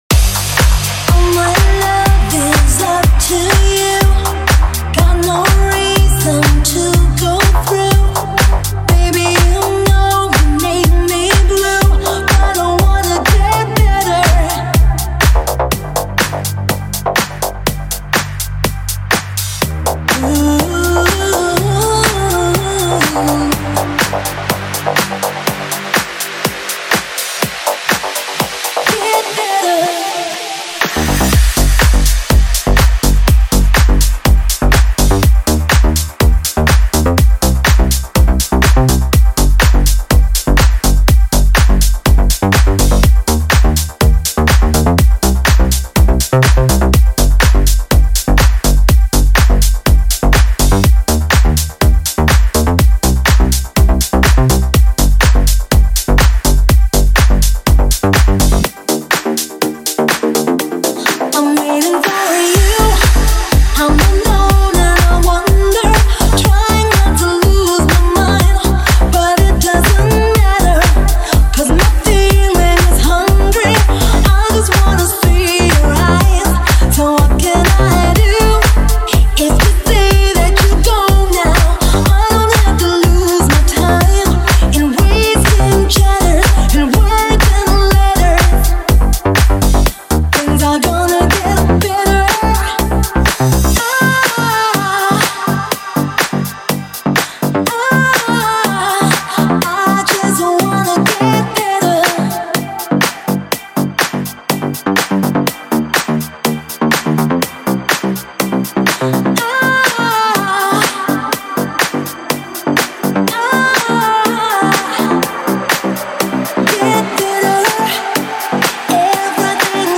Deep House музыка
дип хаус треки